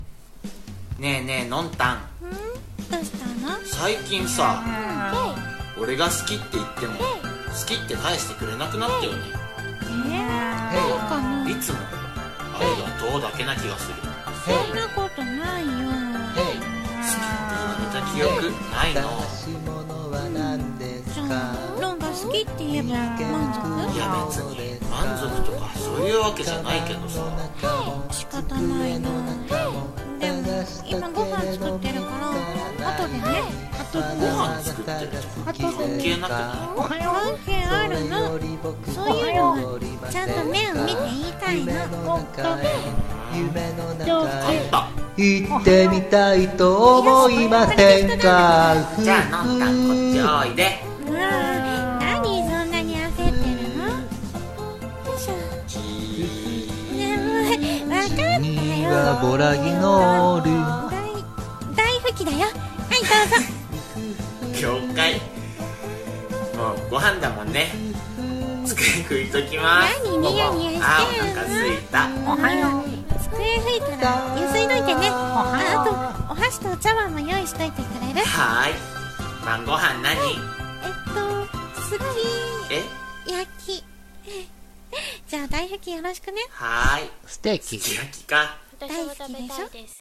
【オリジナル声劇】